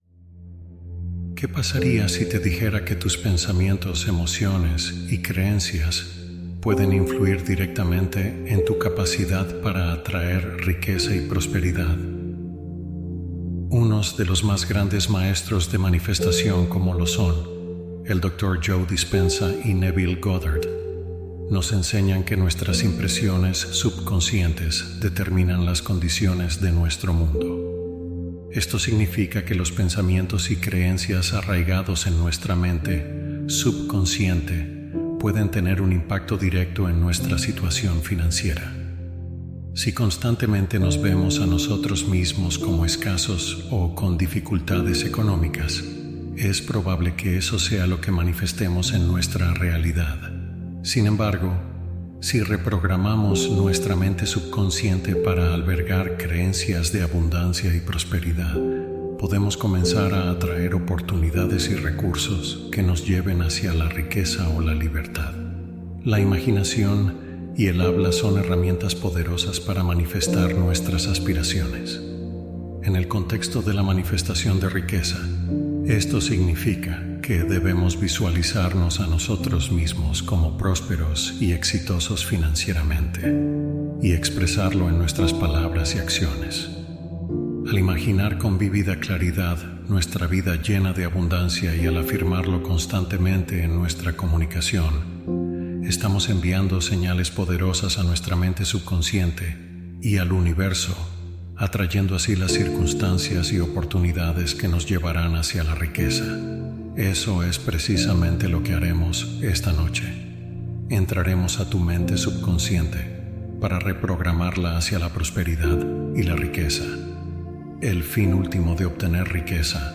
Crea Una Nueva Realidad Mientras Duermes | Hipnosis Guiada